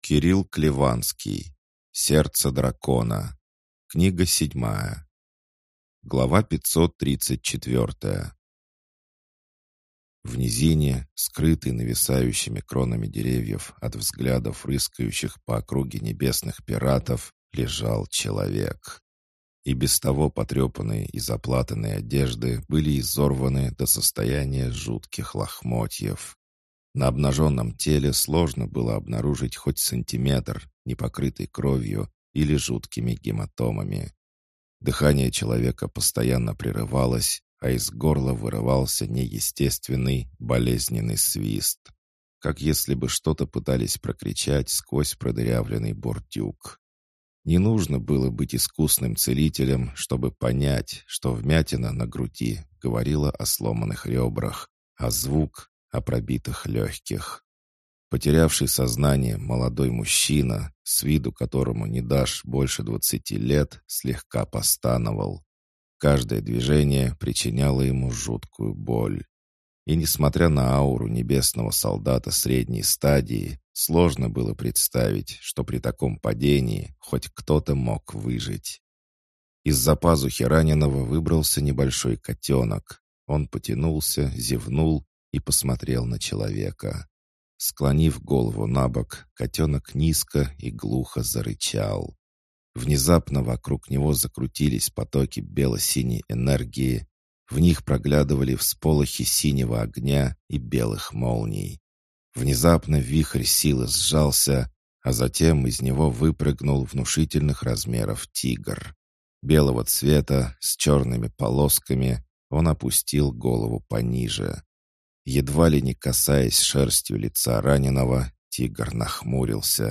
Аудиокнига Сердце Дракона. Книга 7 | Библиотека аудиокниг